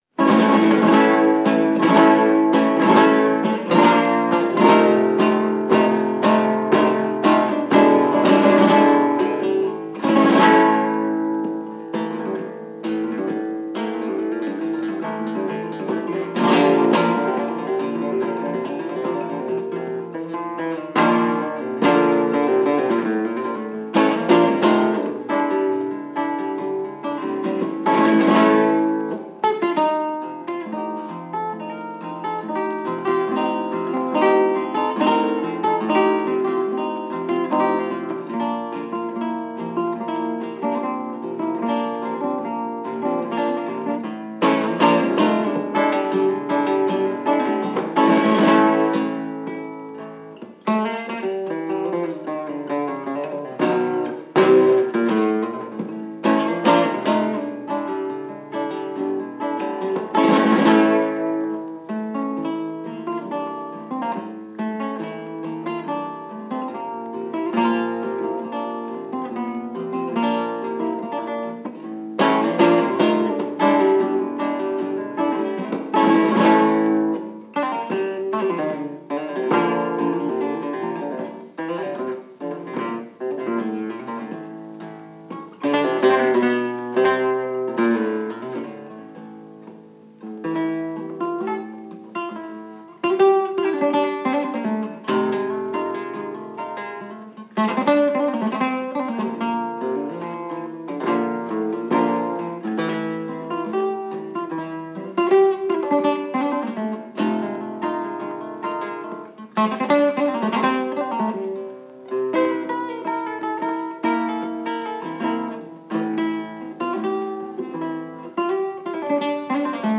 (taranto)
Composition et guitare
Paris, Théâtre des Champs-Élysées, 1954